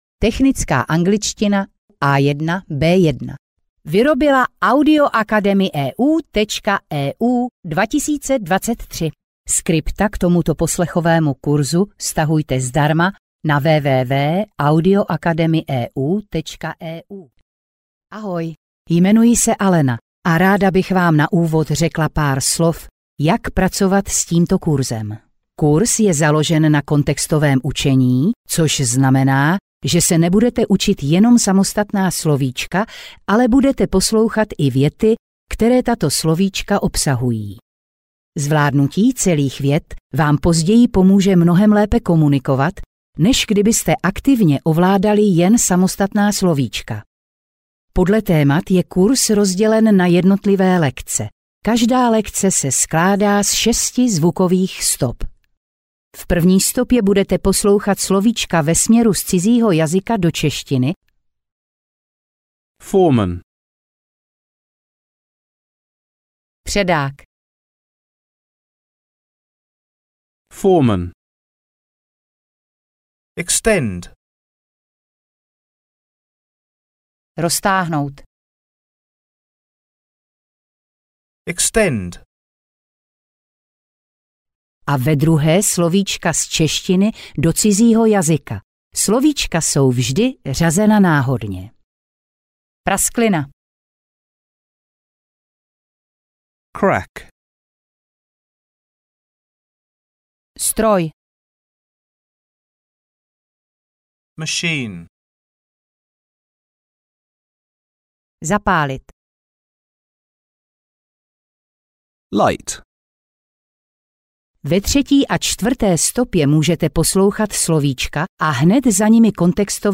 Technická angličtina A1-B1 audiokniha
Ukázka z knihy
Dále máte k dispozici slovíčko následované příkladovou větou, opět v obou variantách překladu (stopa 3 a 4).